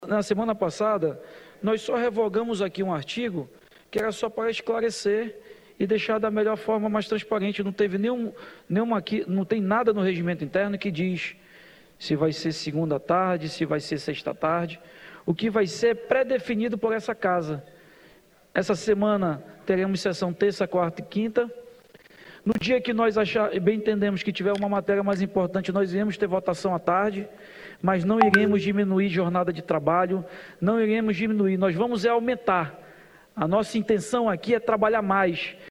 Essa foi a primeira vez que o presidente da Aleam se manifestou sobre a mudança: